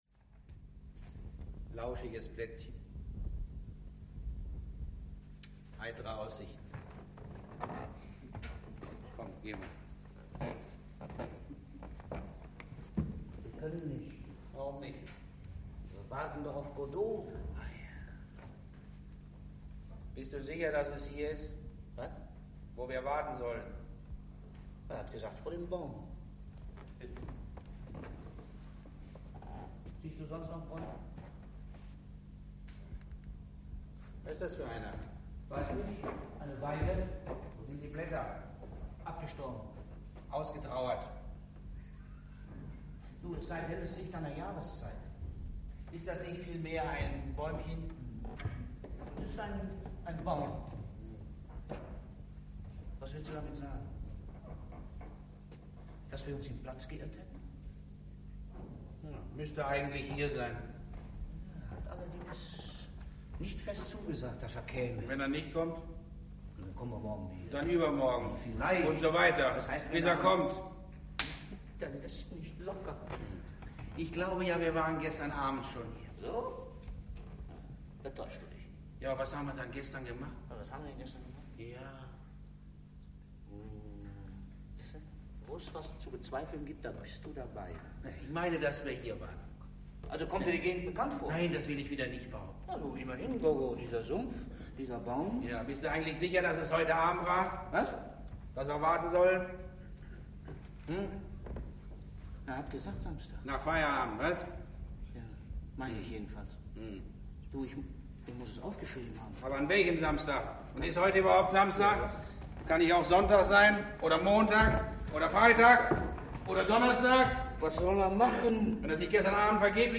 Heinz Rühmann, Martin Wuttke, Samuel Beckett, Billie Whitelaw (Sprecher)